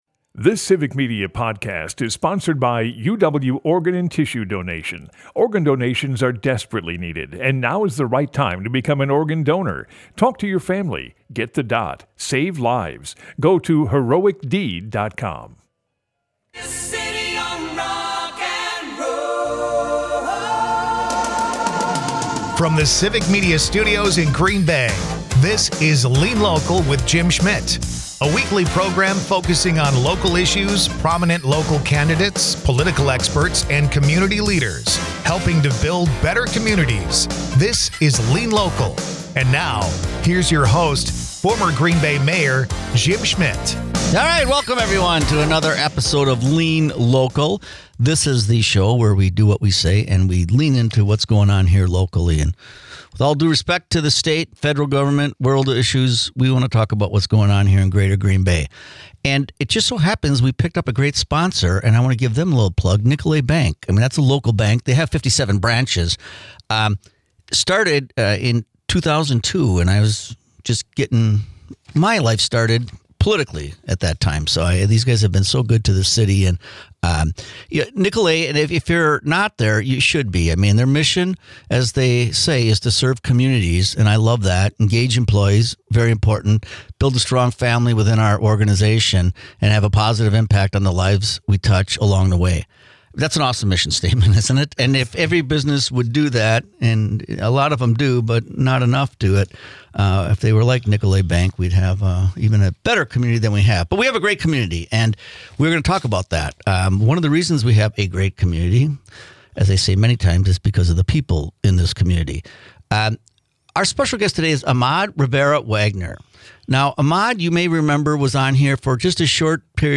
Dive into the heart of community issues with 'Lean Local,' hosted by former Green Bay Mayor Jim Schmitt.
lean local Lean W/ Amaad Rivera-Wagner Guests: Amaad Rivera-Wagner 12/8/2024 Listen Share Former Green Bay Mayor Jim Schmitt is joined by elect for District 90, Amaad Rivera-Wagner . Amaad talks about what motivated him to run in politics in Wisconsin, What's important to Amaad, and being sworn in Jan.6th. Jim asks Amaad, what he think of the current state of Green Bay, and Wisconsin.